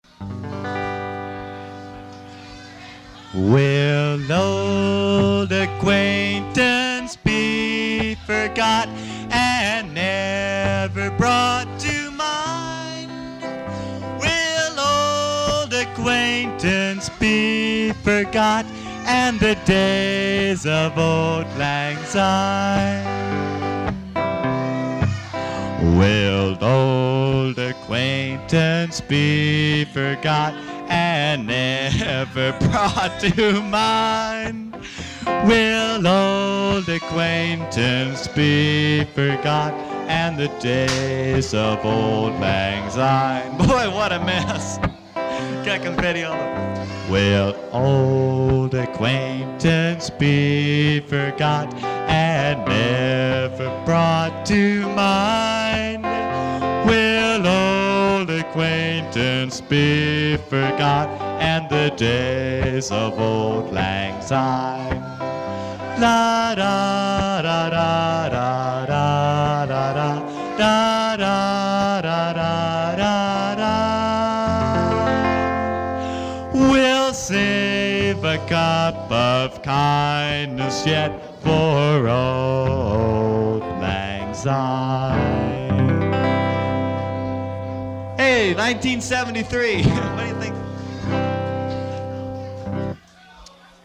Live
Traditional melody